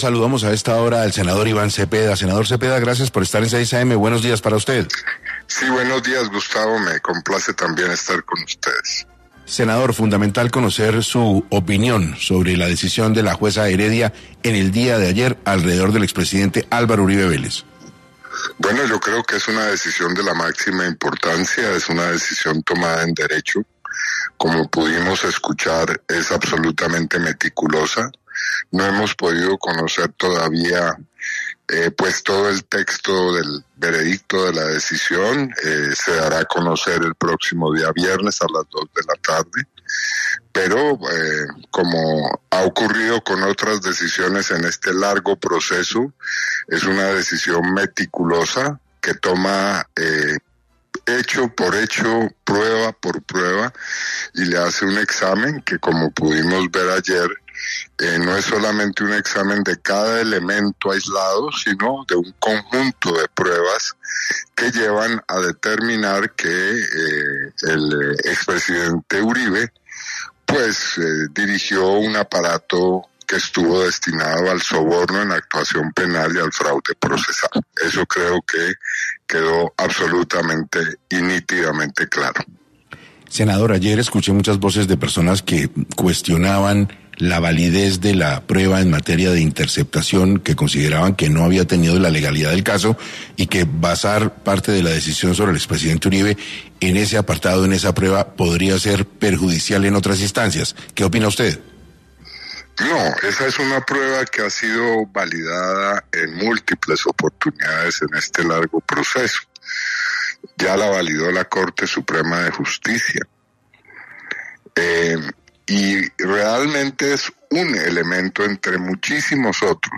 En Caracol Radio estuvo el senador Iván Cepeda explicando los motivos por los que comenzó el caso.